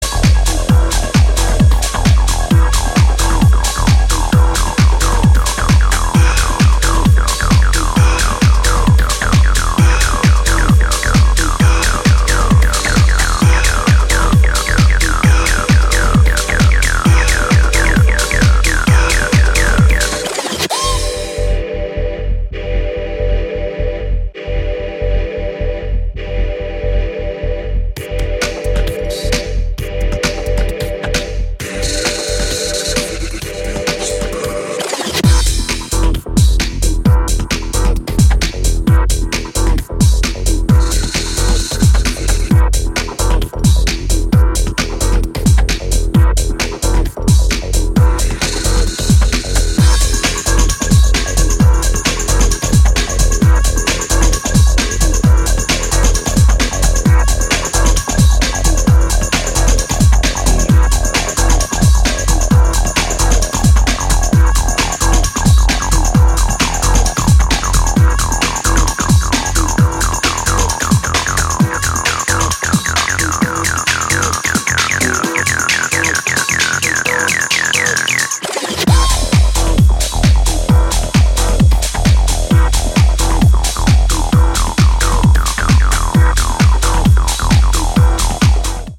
ピークタイム路線の強力な内容ですね。